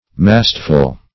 Search Result for " mastful" : The Collaborative International Dictionary of English v.0.48: Mastful \Mast"ful\, a. [See 1st Mast .]